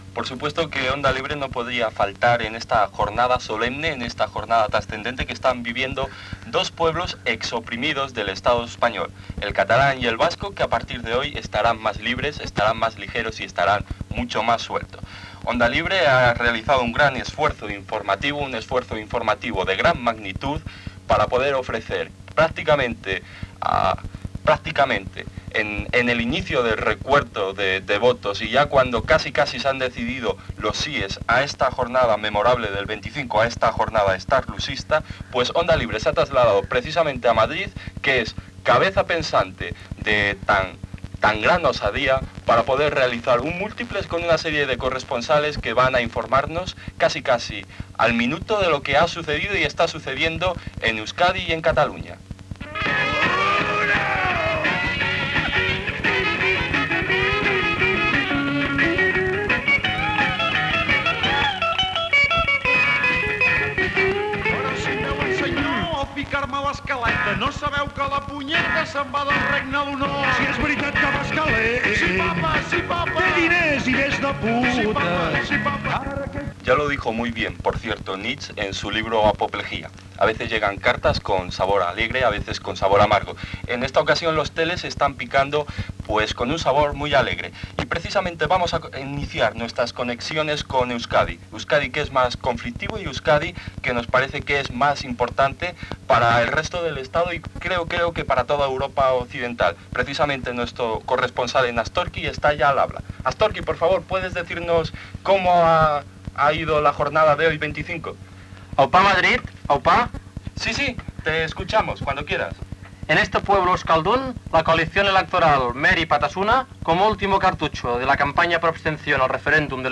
Paròdia de múltiplex informatiu sobre les votacions dels estatuts del País Basc i Catalunya.
Entreteniment